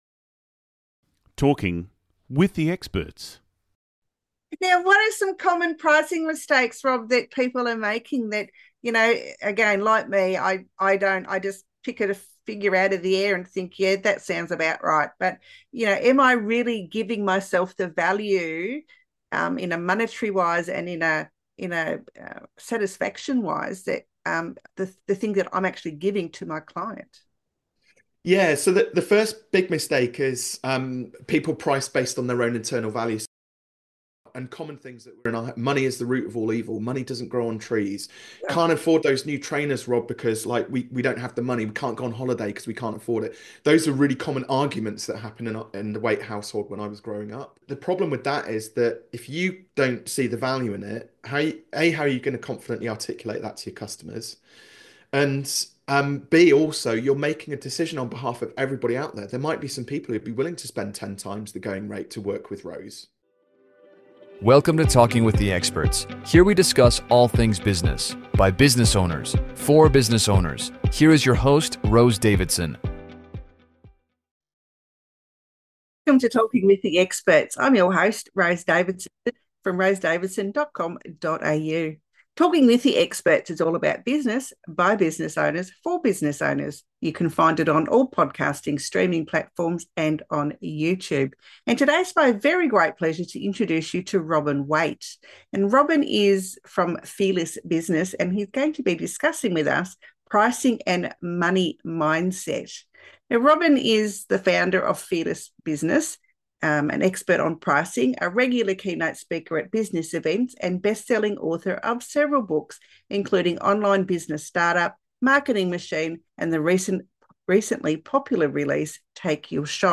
He also discusses the importance of cultivating a positive money mindset, enabling business owners to overcome self-imposed limitations and embrace financial success. Three Key Points from the Interview: Overcoming Pricing Fears: Strategies to help business owners confidently raise their prices without fear of losing clients.